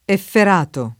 effer#to] agg.